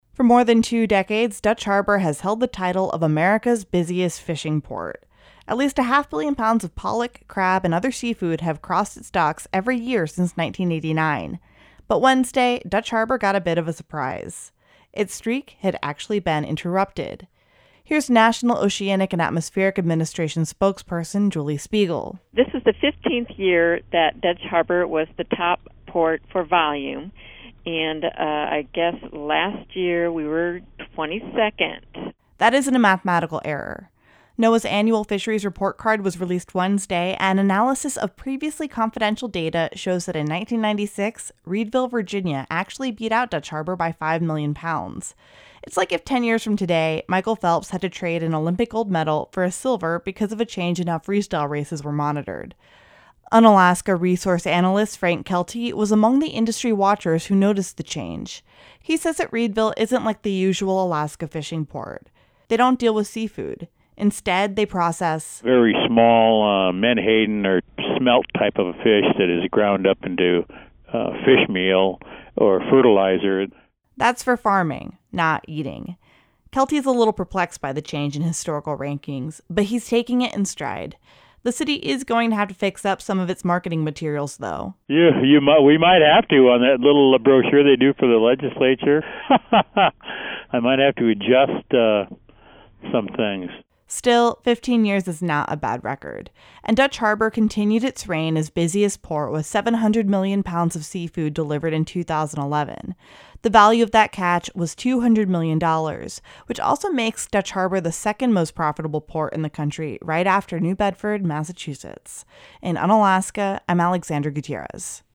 20_ports_-_pkg.mp3